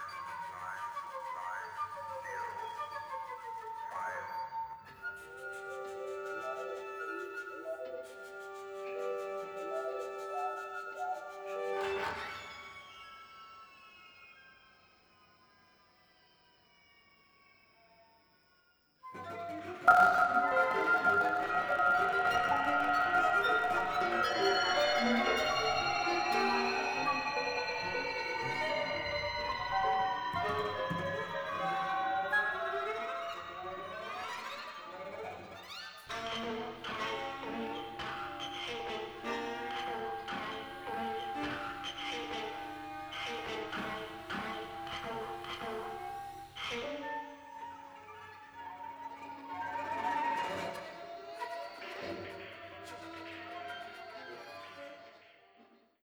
Orpheum Annex, Vancouver, Canada